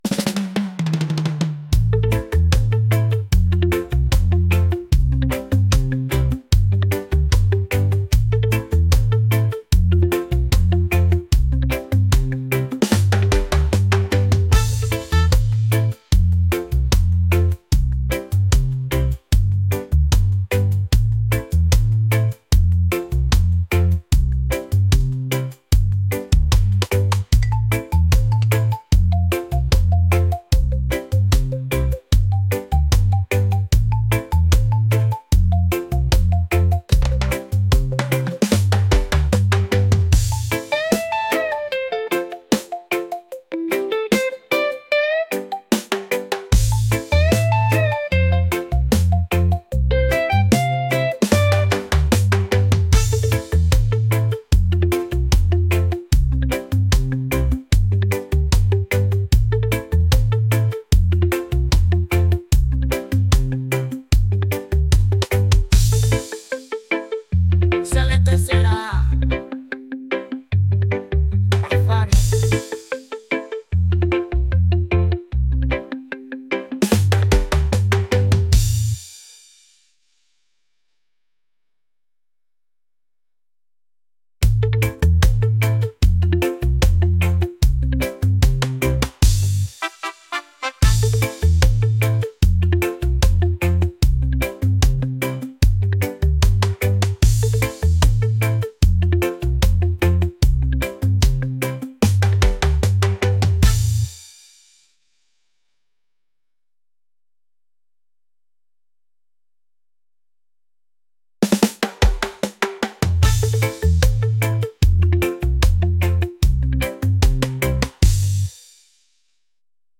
island | upbeat | reggae